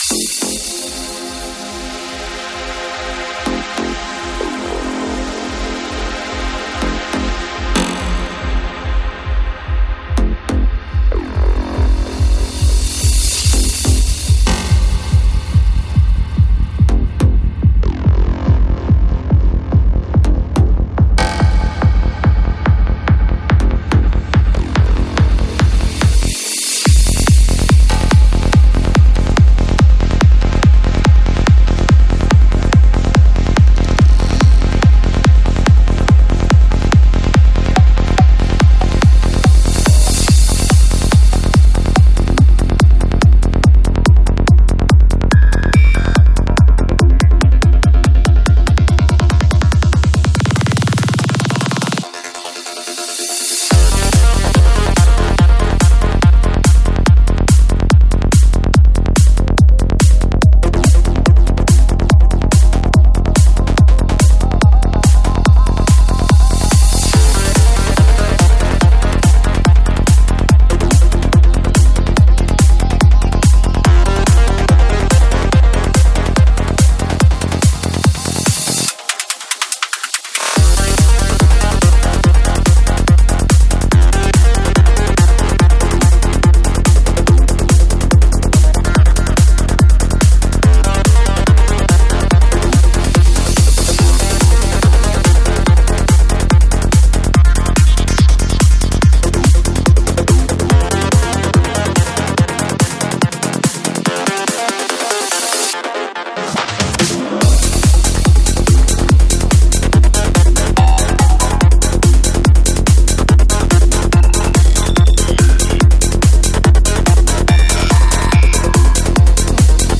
Жанр: Trance